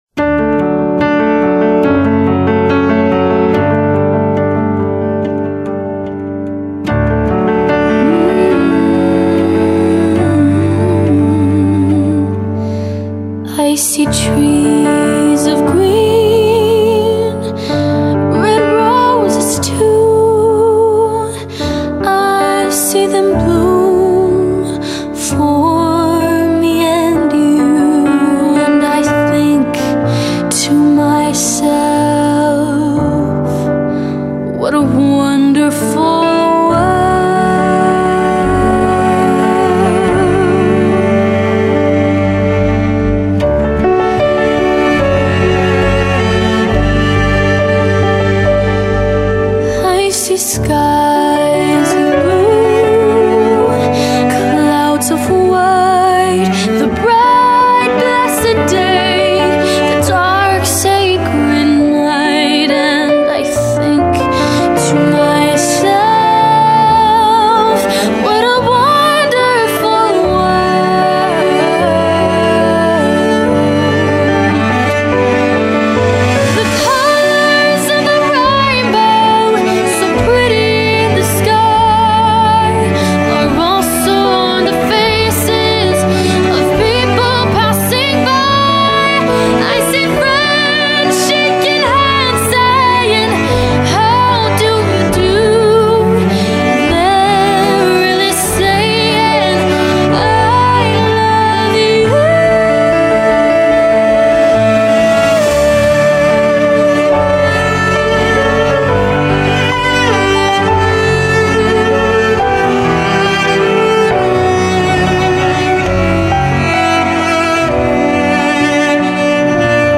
Piano Version